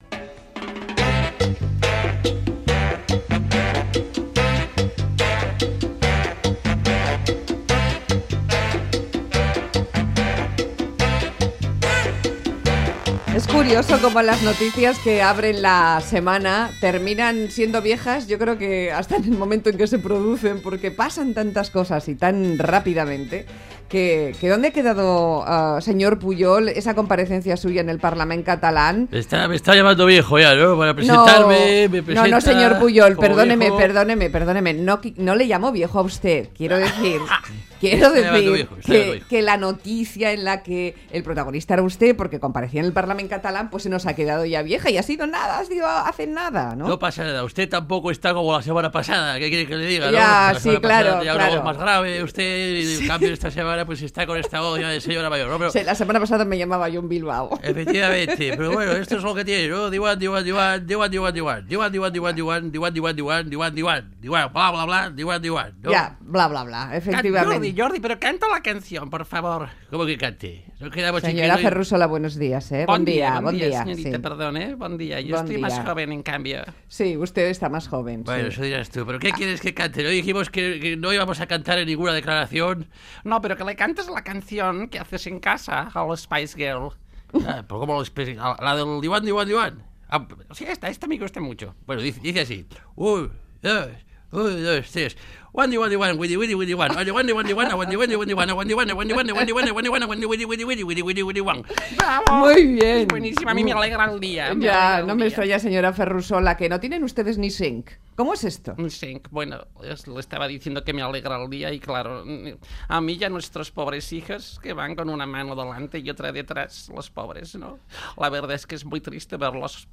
La actualidad, pasada por imitaciones de las voces de la actualidad | EITB